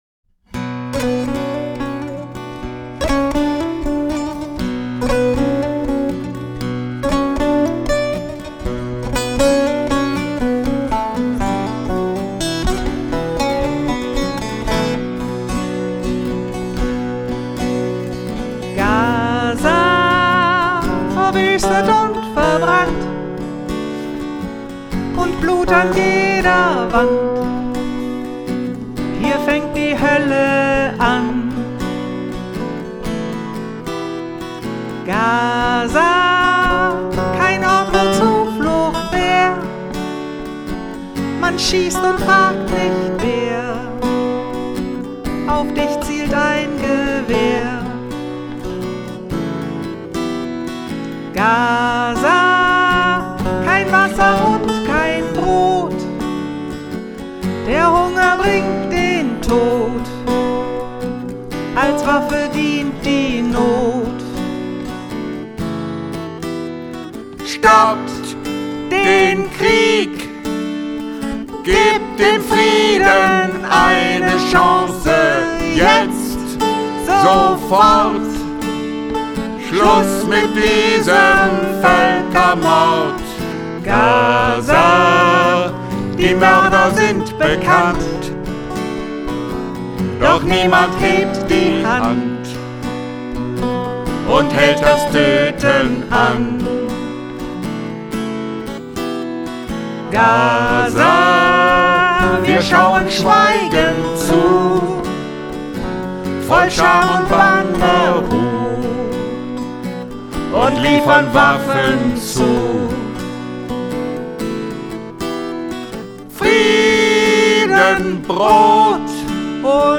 Bağlama